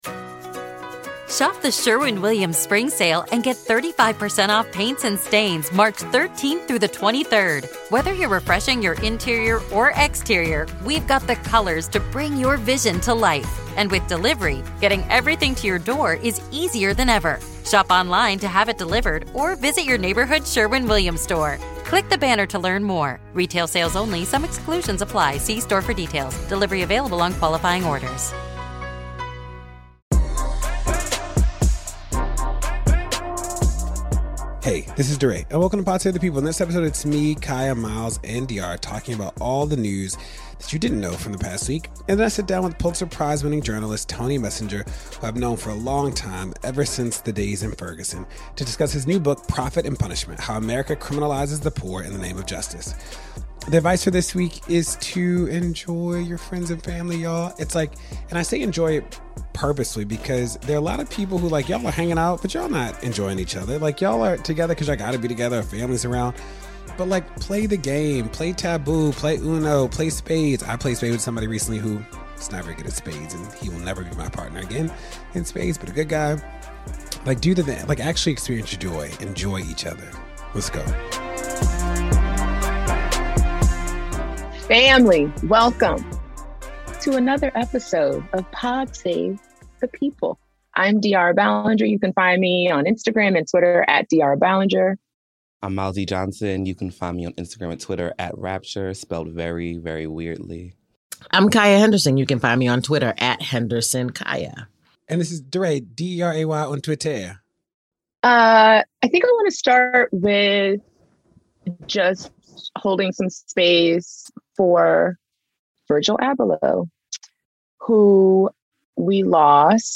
DeRay interviews Pulitzer Prize-winning journalist Tony Messenger on his book PROFIT AND PUNISHMENT: How America Criminalizes the Poor in the Name of Justice.